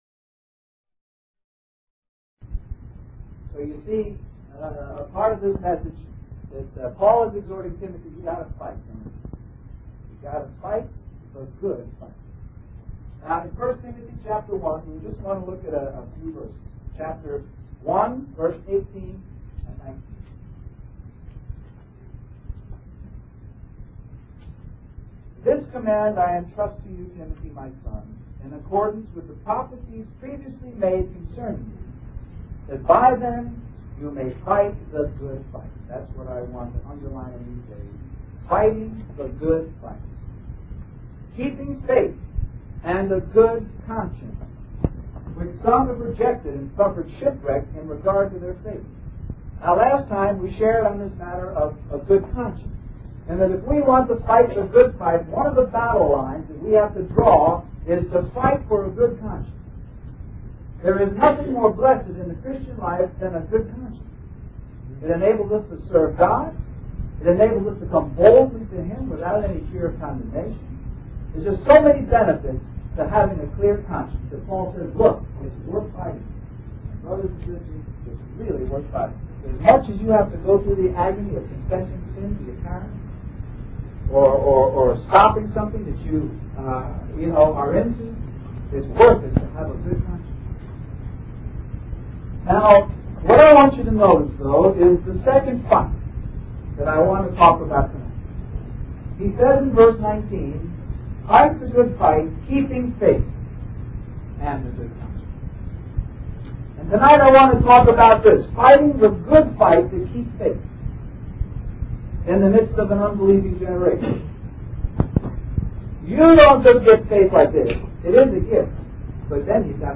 Christian Family Conference We apologize for the poor quality audio